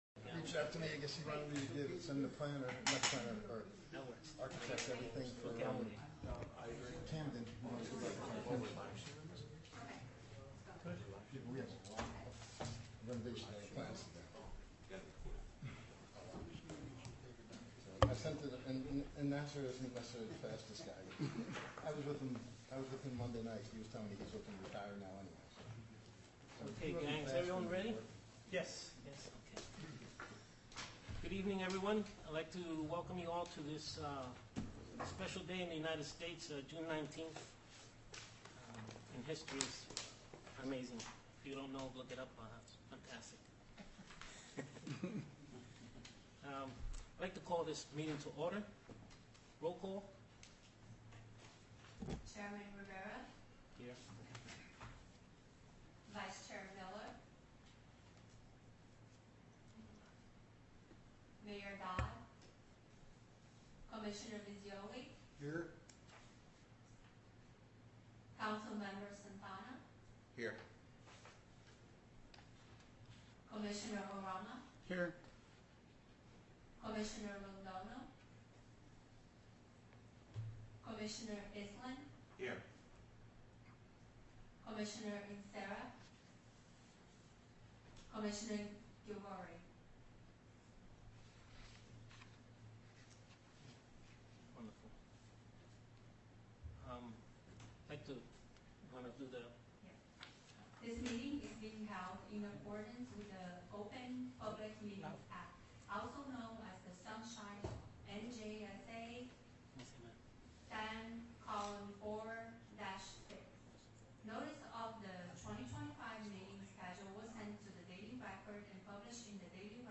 Meeting Type : Planning Board